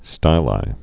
(stīlī)